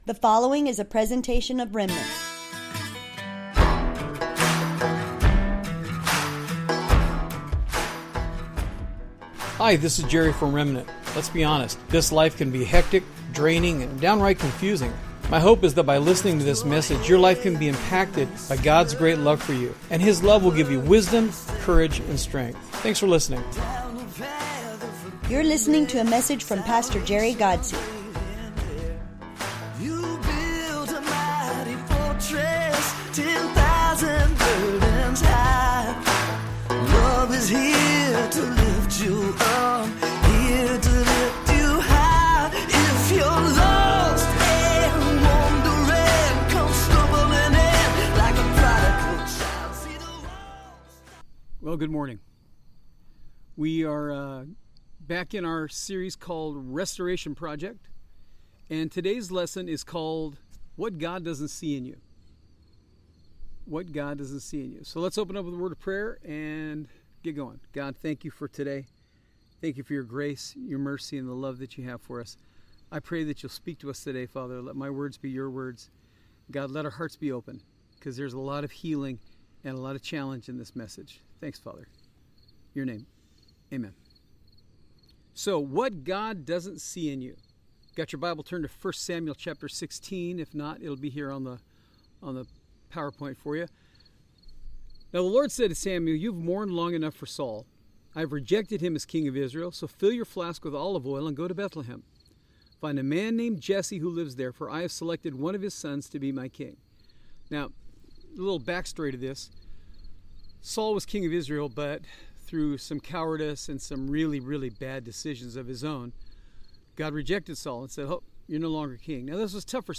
A Bible study from Remnant Church in El Centro, CA